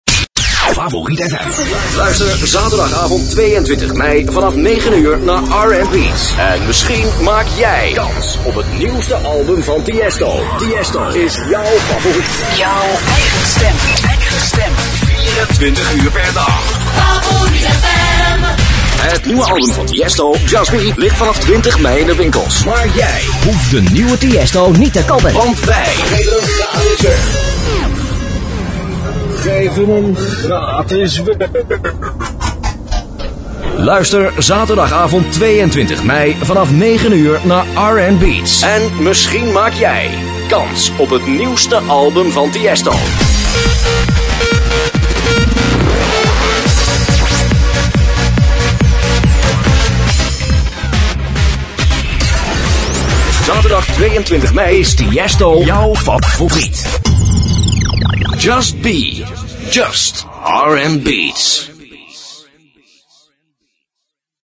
Op deze pagina tref je een aantal voorbeelden van jingles en promo's (in MP3 formaat) aan die vrij recentelijk door mij zijn ingesproken.